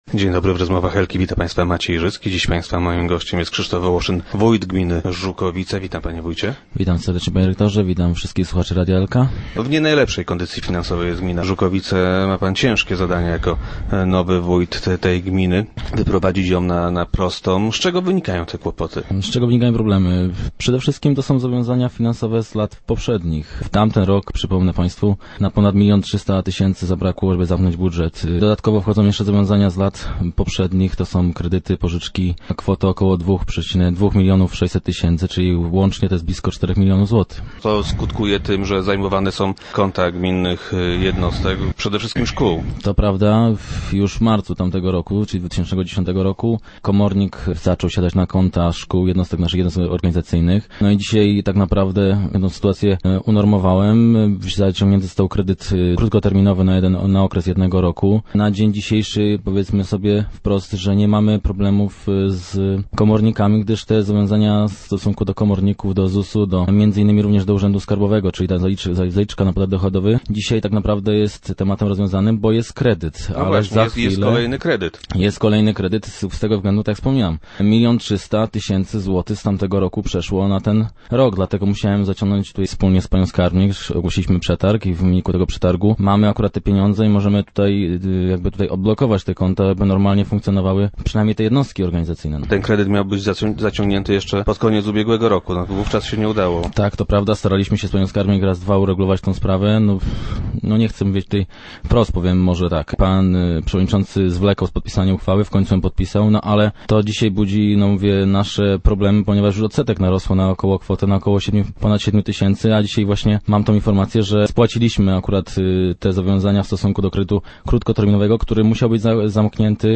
- Przygotowałem kilka wariantów. Ten wybrali radni - twierdzi Krzysztof Wołoszyn, który był gościem Rozmów Elki.